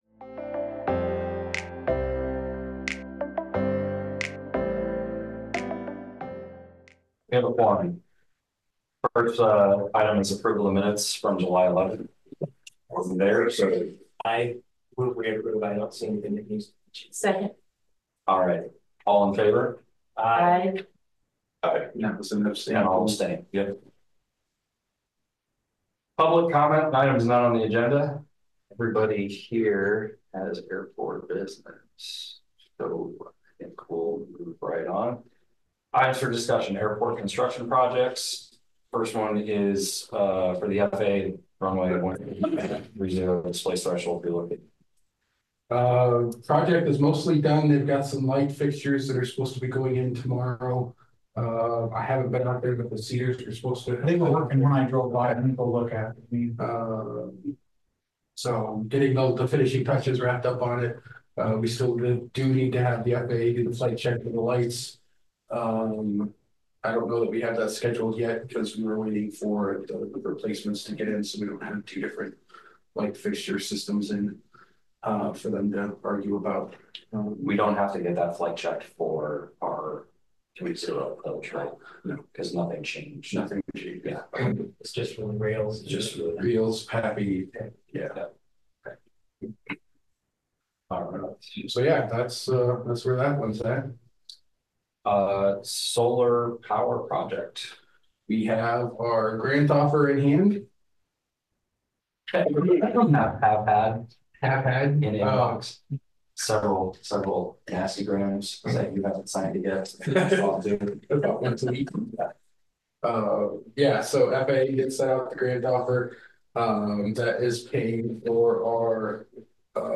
Regular monthly meeting of the City of Iowa City's Airport Commission, rescheduled from August 8.